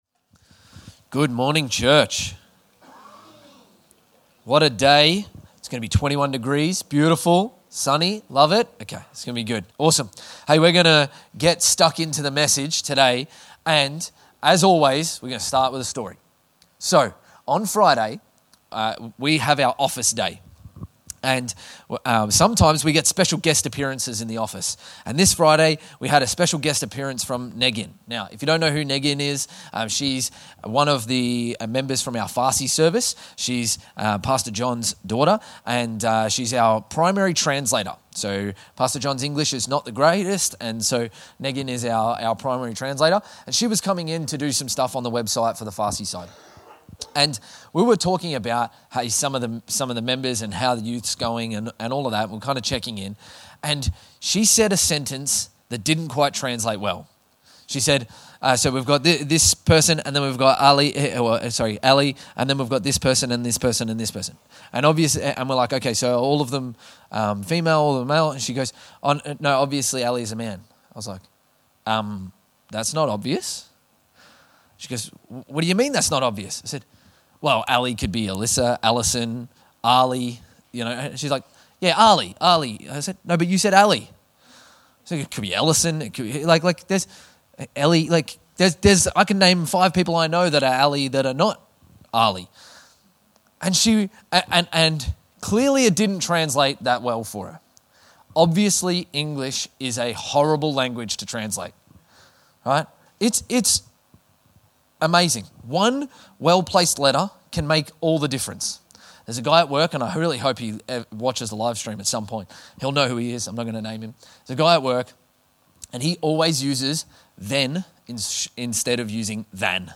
2024 Current Sermon As Led By